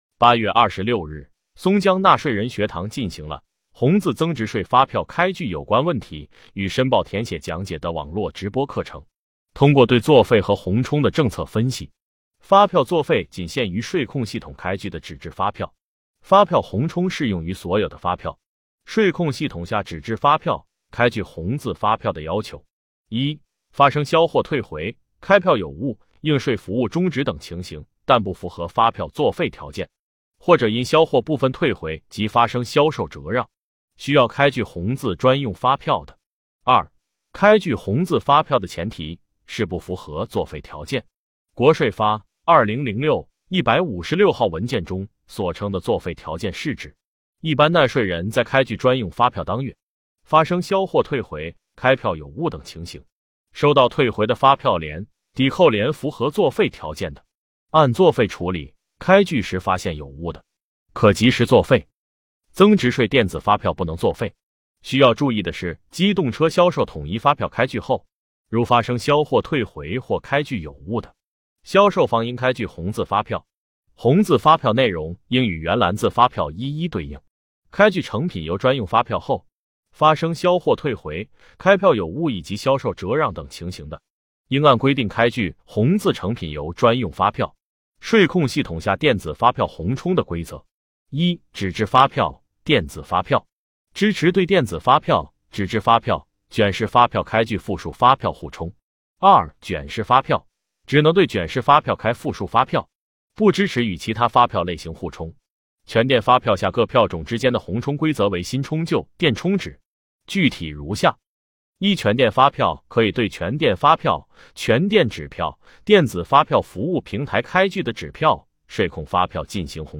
目前，松江区税务局通过网络直播的形式开展纳税人学堂。